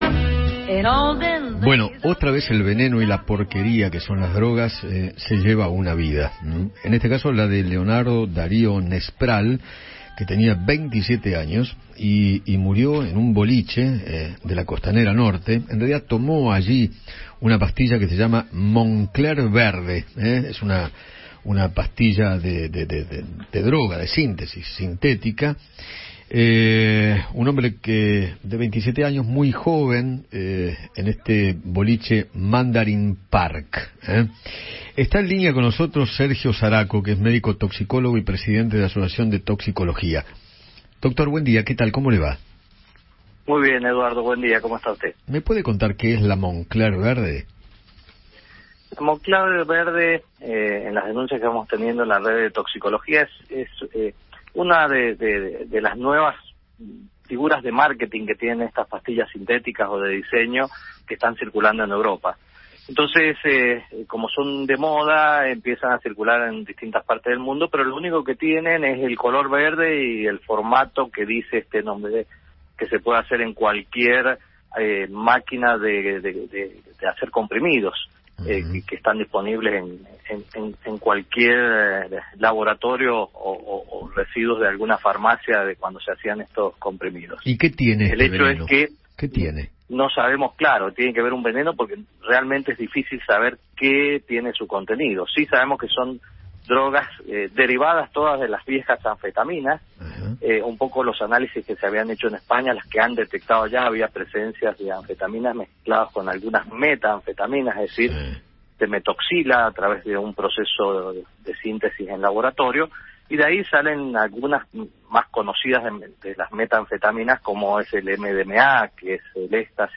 dialogó con Eduardo Feinmann sobre la muerte de un joven de 27 años